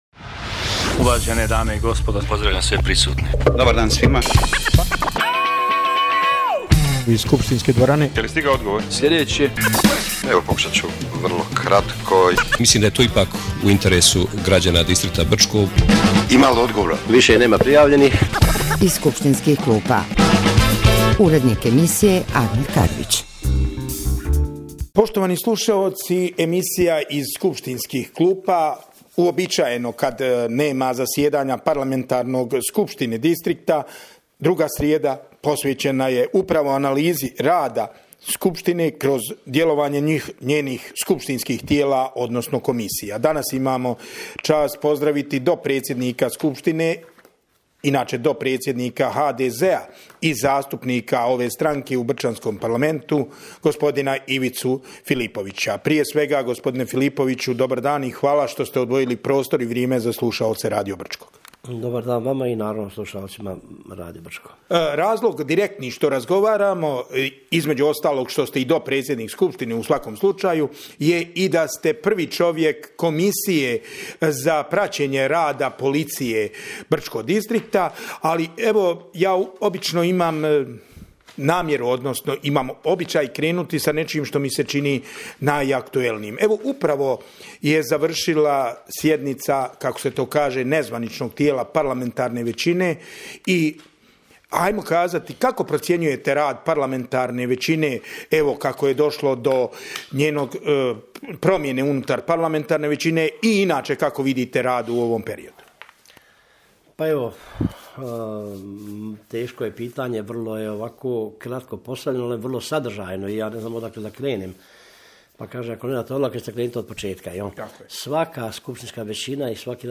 Gost emisije “Iz skupštinskih klupa” – potpredsjednik Skupštine BD BiH Ivo Filipović